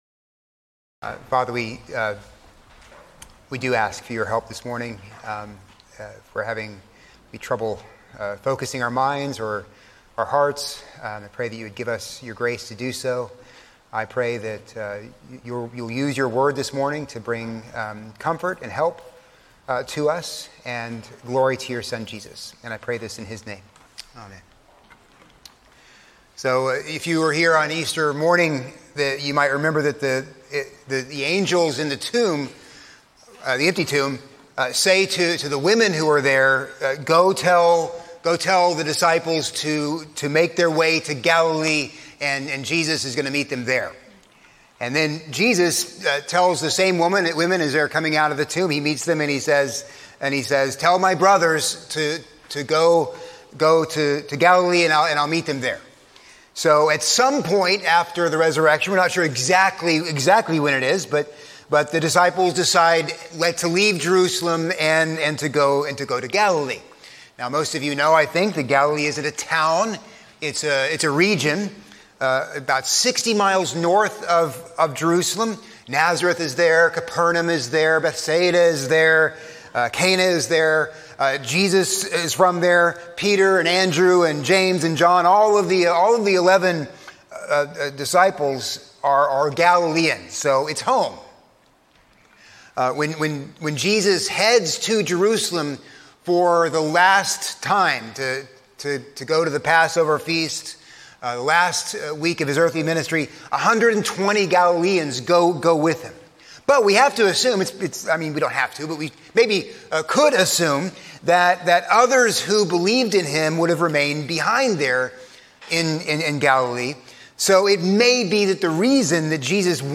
A sermon on John 21:1-14